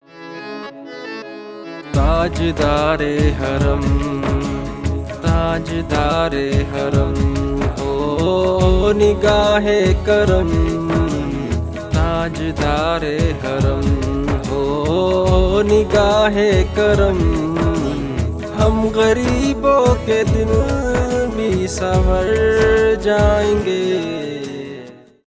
поп
cover